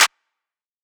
MZ Clap [Plugg Lo].wav